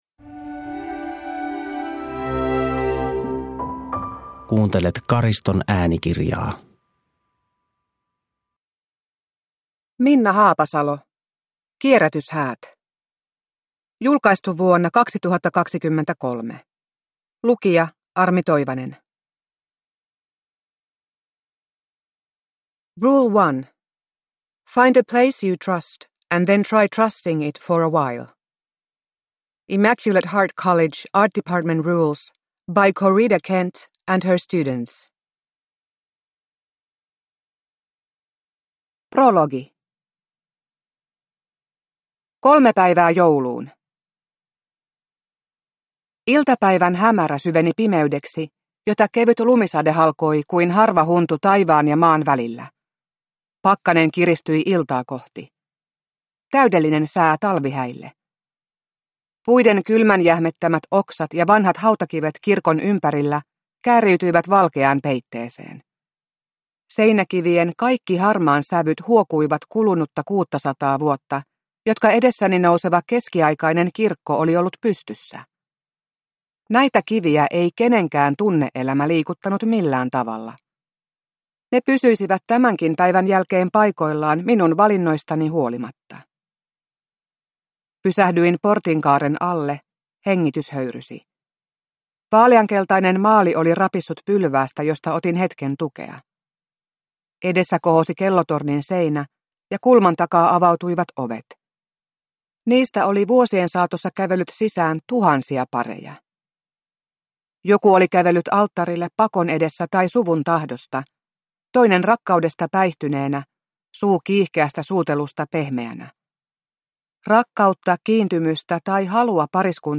Kierrätyshäät – Ljudbok – Laddas ner
Uppläsare: Armi Toivanen